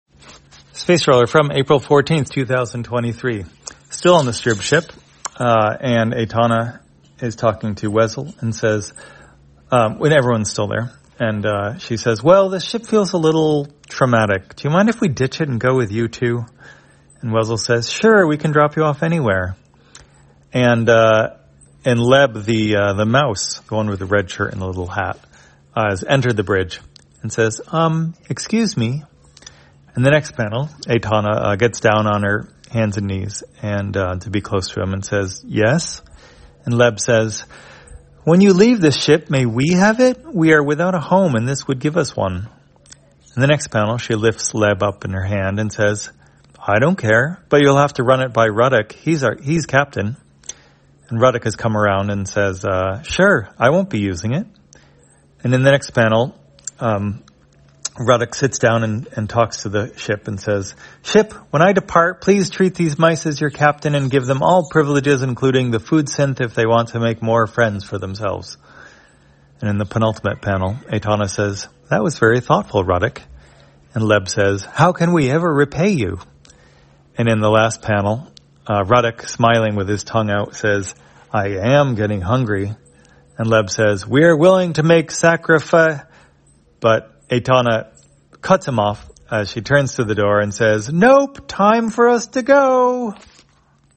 Spacetrawler, audio version For the blind or visually impaired, April 14, 2023.